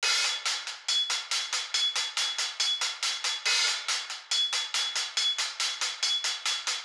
Fly HiHat Loop.wav